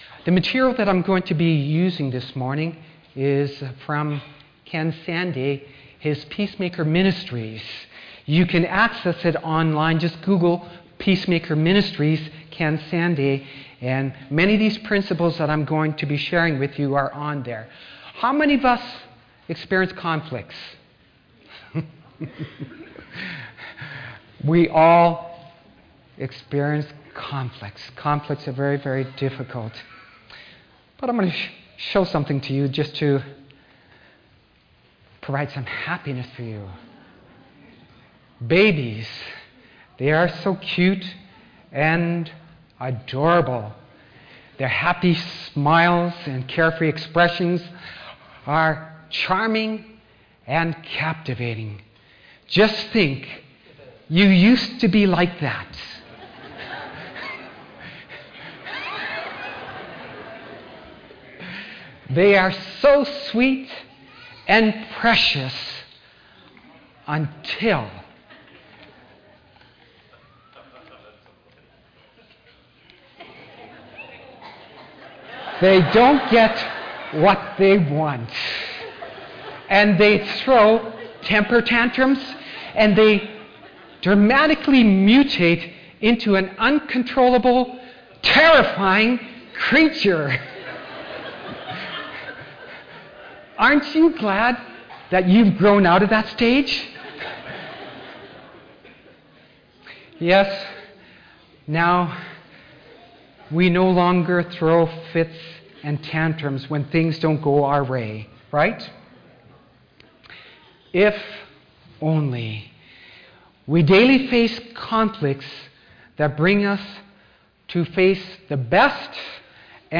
Service Type: Sabbath Afternoon